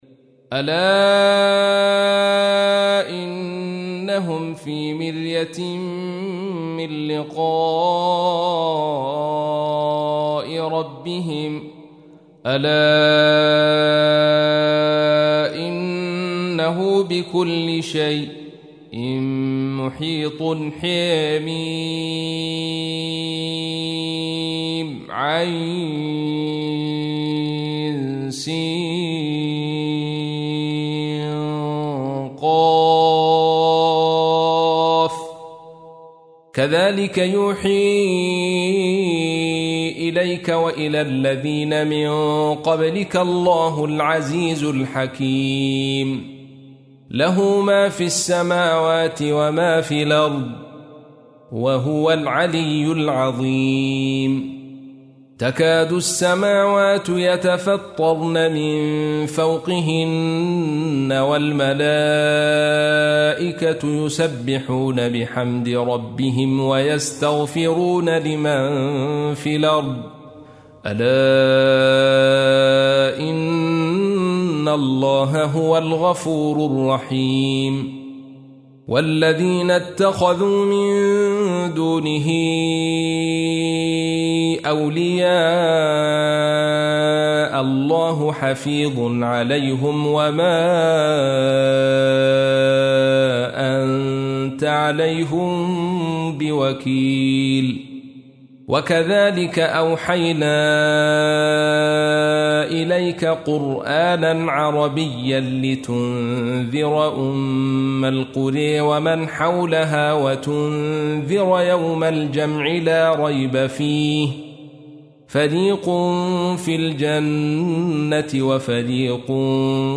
تحميل : 42. سورة الشورى / القارئ عبد الرشيد صوفي / القرآن الكريم / موقع يا حسين